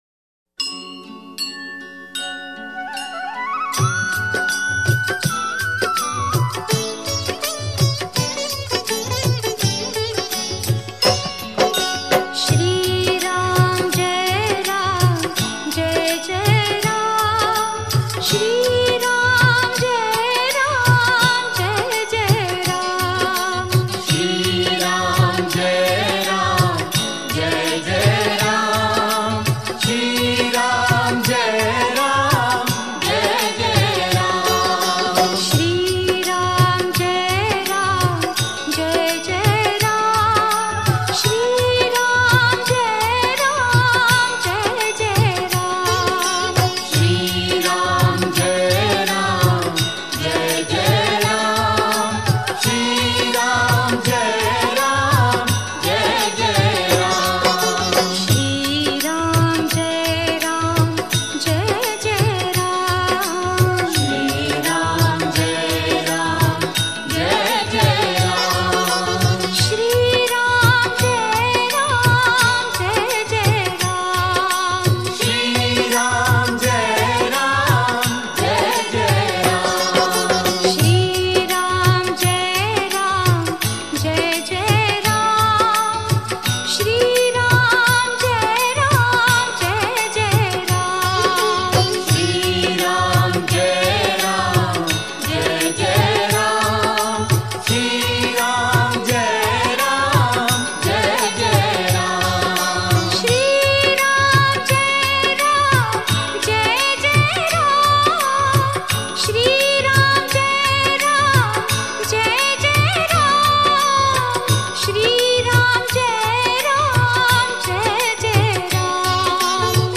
Devotional Songs > Shree Ram Bhajans